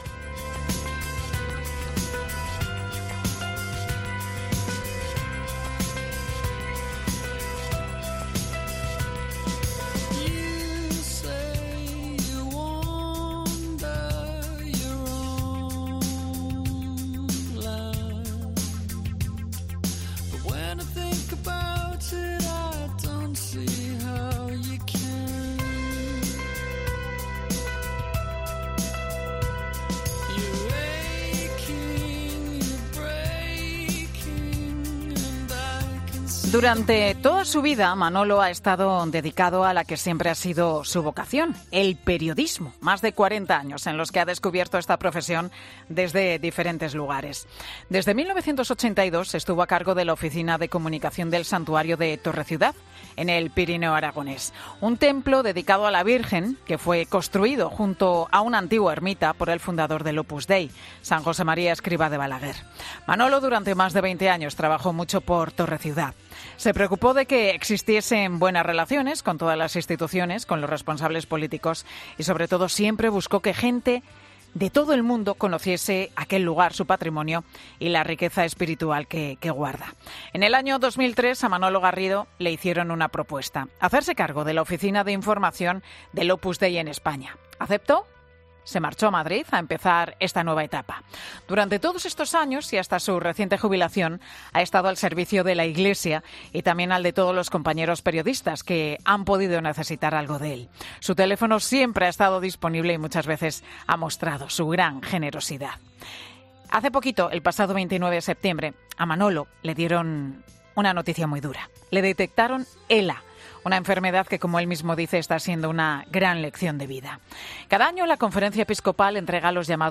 Hoy nos atiende en Mediodía COPE.